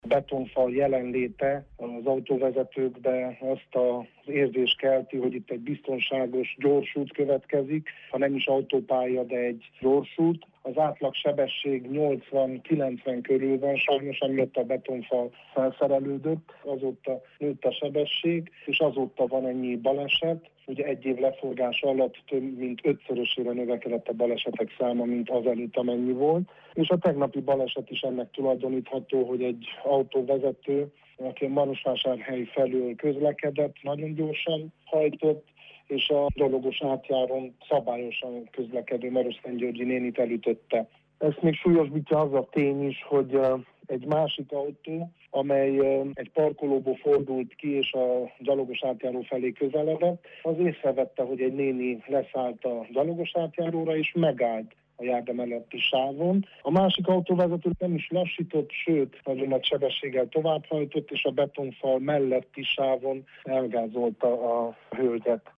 A marosszentgyörgyiek régóta kérik az útelválasztó betonfal eltávolítását. Sófalvi Szabolcs, marosszentgyörgyi polgármestert hallják.